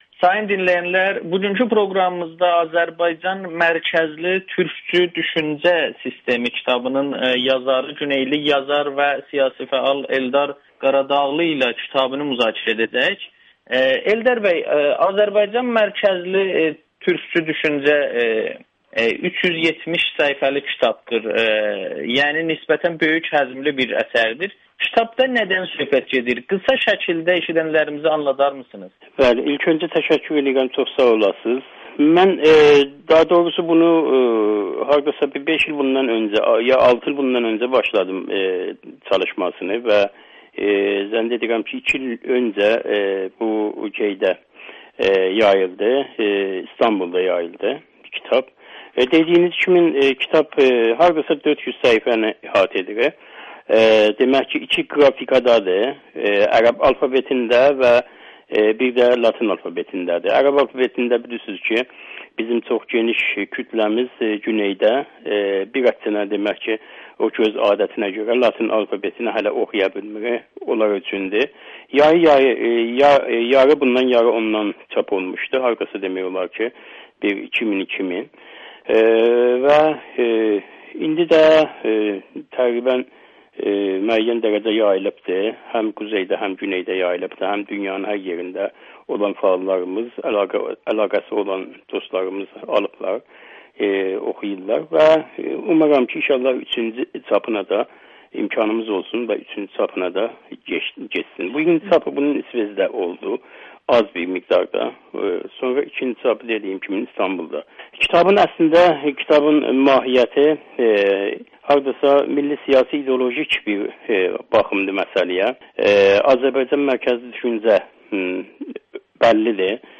Yazıçı və milli fəal Amerikanın Səsinə müsahibə verib